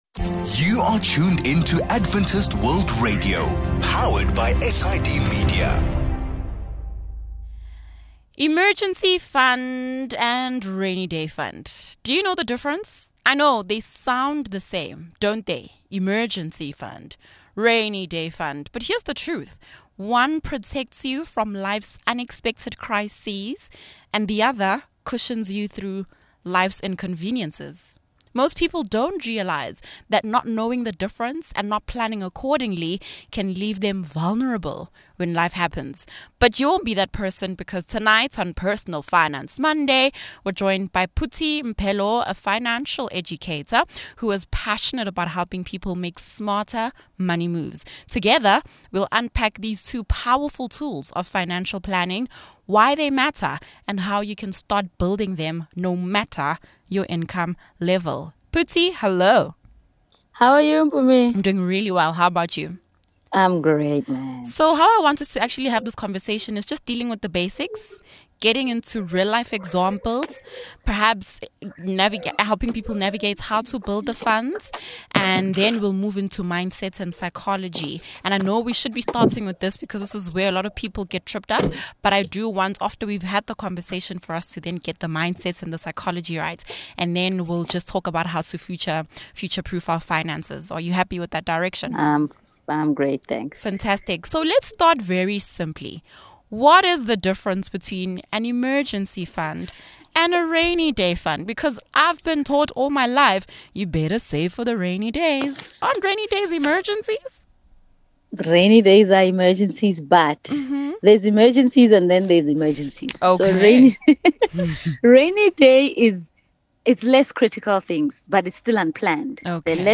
Two of the most powerful savings tools for weathering financial surprises are a rainy-day fund and an emergency fund. In today’s conversation, we explore the difference between the two and learn why we need both.